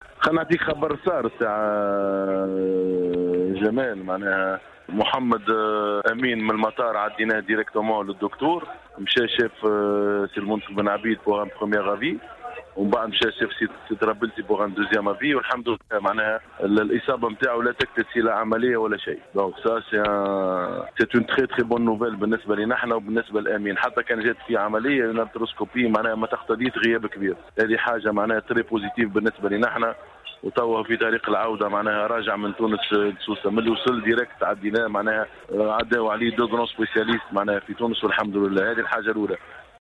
أكد الناخب الوطني لكرة القدم نبيل معلول خلال مداخلته اليوم في برنامج قوول مع...